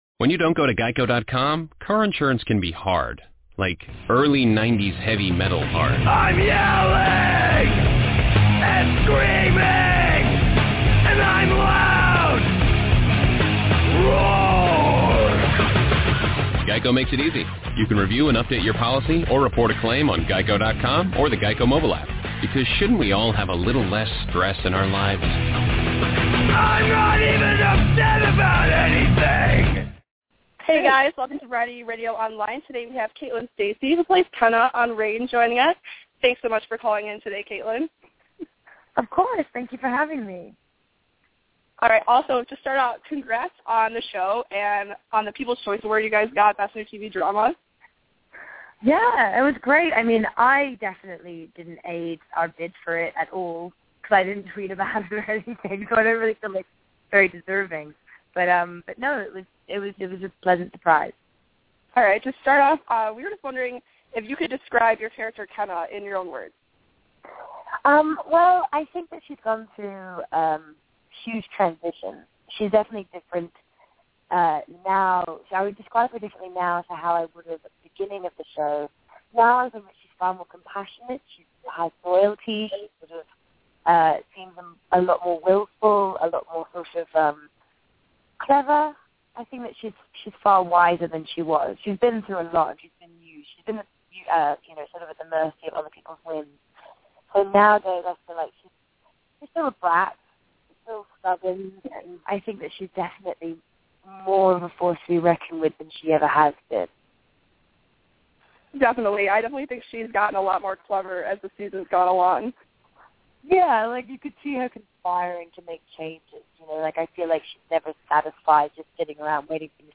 Caitlin Stasey called in to the VRO to chat about her role as Kenna on the hit CW show Reign, what life was like on set in Toronto, and how those pretty dresses aren’t all they’re cracked up to be.